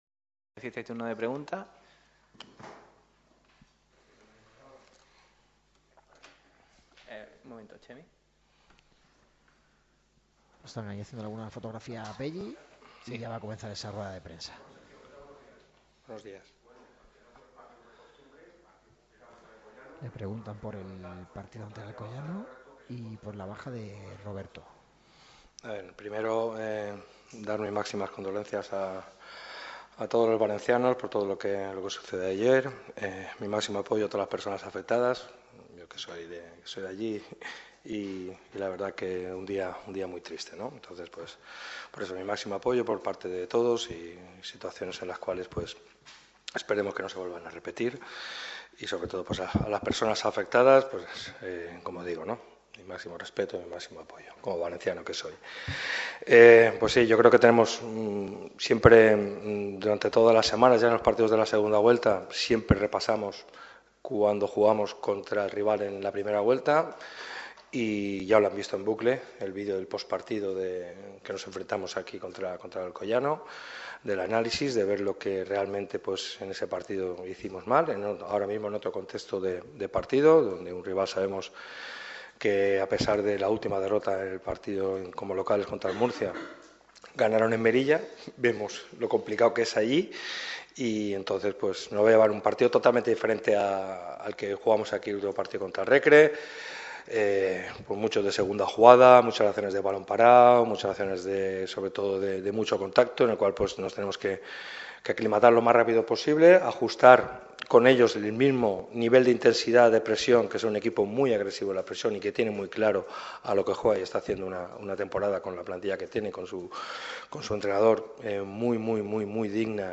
El técnico de Nules ha comparecido en la sala de prensa ‘Juan Cortés’ del estadio La Rosaleda con motivo de la previa del partido frente al Alcoyano del próximo sábado a las 18:00 horas. Sergio Pellicer y el Málaga CF afrontan una de las salidas más complicadas de la temporada y el técnico ha repasado las claves del mismo.